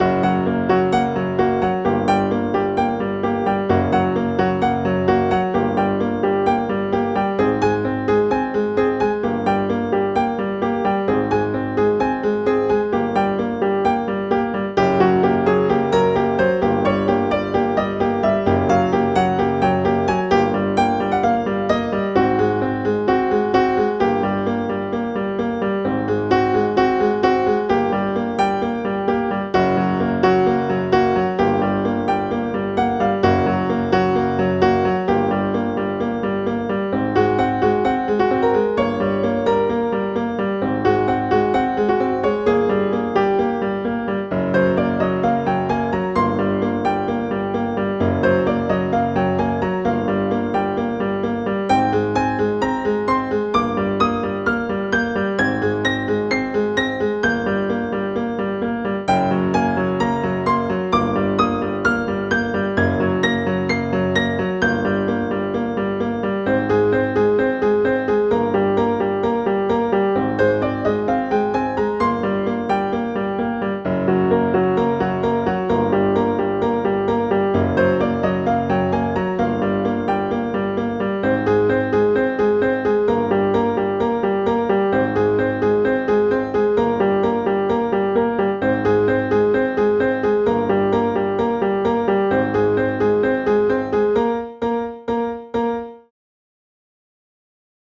Solo de piano minimalista (escena de resolución)
piano
melodía
repetitivo
solo